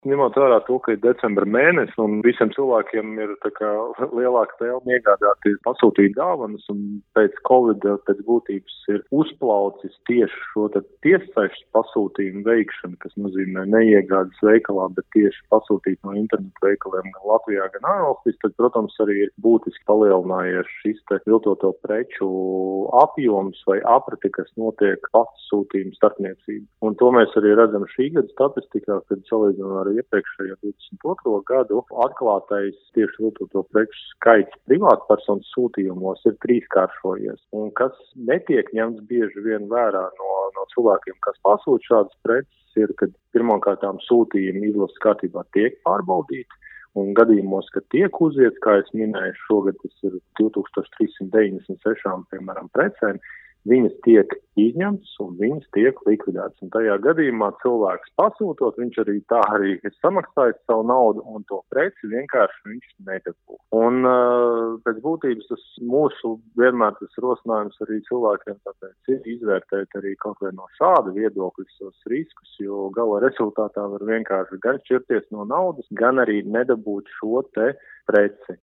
Arvien populārāka kļūst preču iegāde internetā, taču tā var sagādāt arī vilšanos. Kas būtu jāņem vērā izvēloties preces internetā, “RADIO SKONTO” pastāstīja Patentu valdes direktors Agris Batalauskis.
RADIO SKONTO Ziņās par viltotām precēm internetā